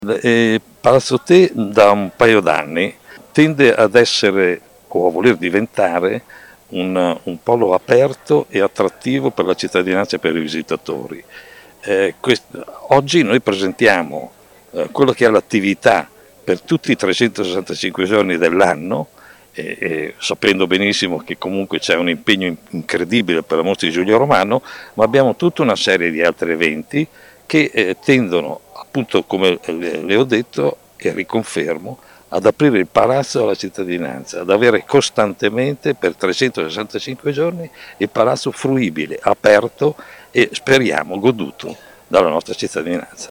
Molti altri sono i progetti in corso d’opera a Palazzo Te, come confermato dalle personalità intervistate dal nostro corrispondente